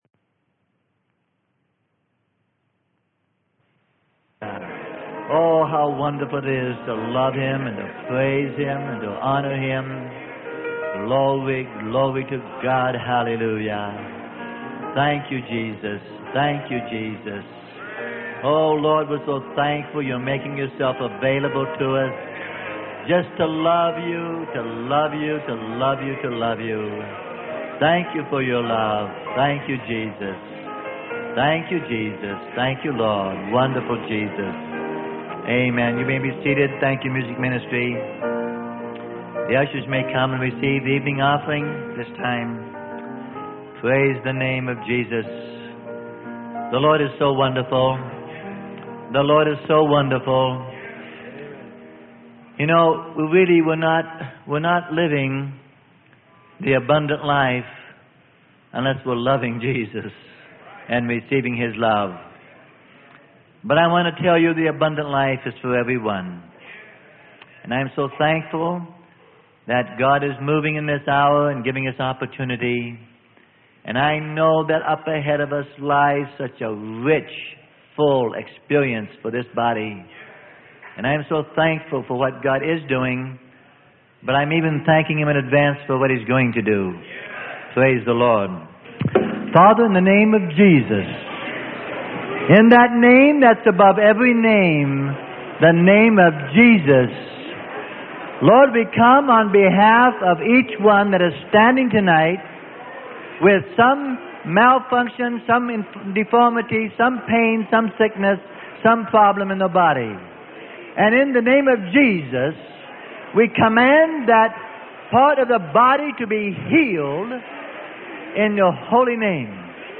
Sermon: It Is Finished - Freely Given Online Library